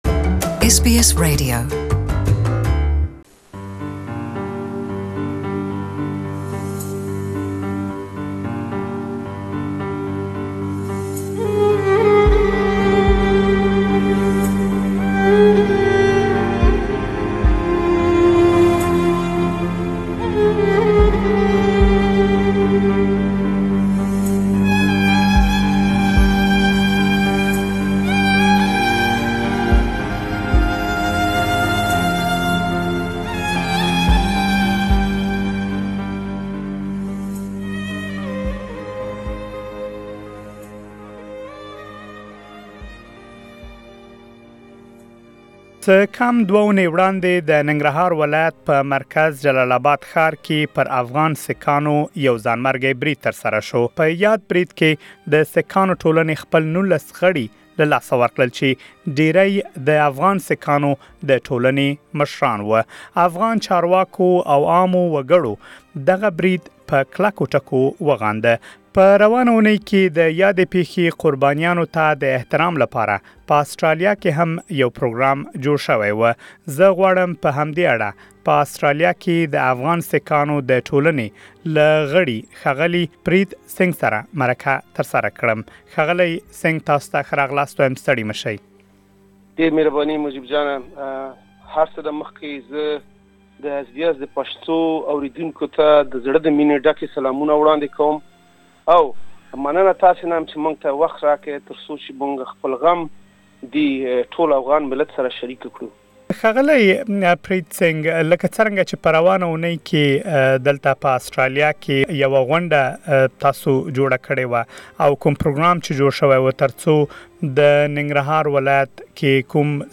Interview with a member of the Afghan Sikh Community in Australia